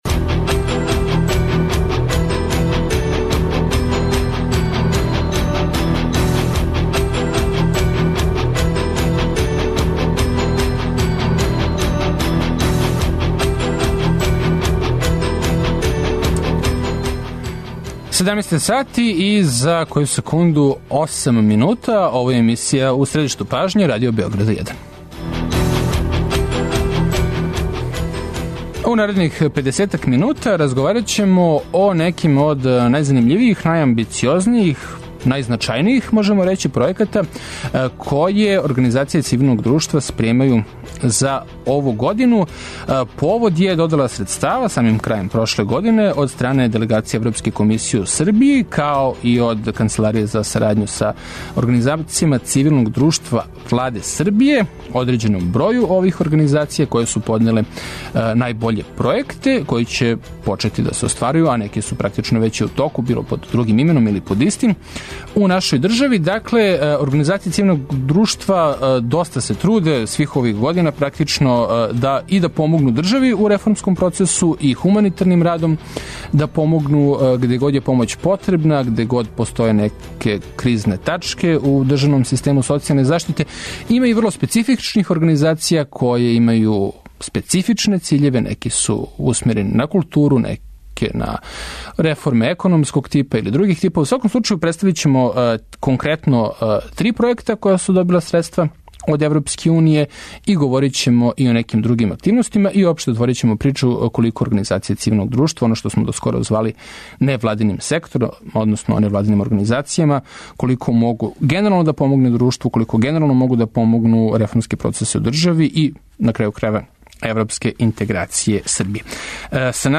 Европска Унија доделила је средства за пројекте који ће помоћи реформу државне управе, промоцију културне разноликости и развој цивилног друштва. Наши гости су представници организација које су добиле средства ЕУ.